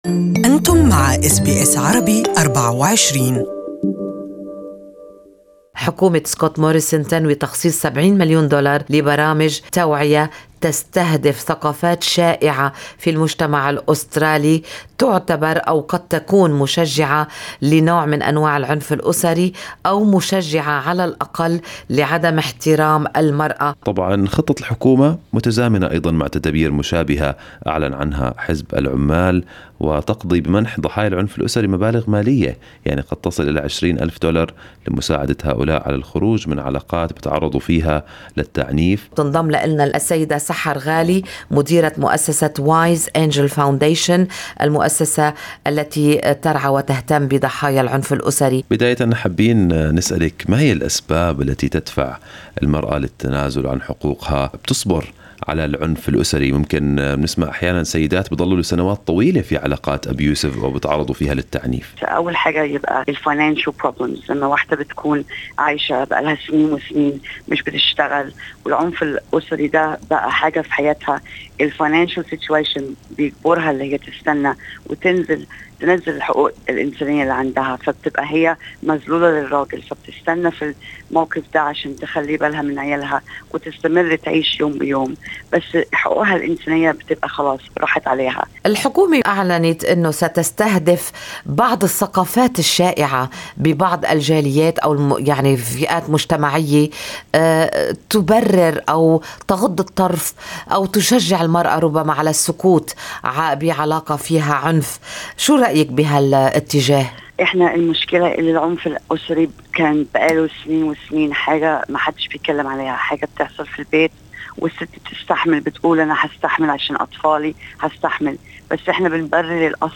SBS Arabic24 interviewed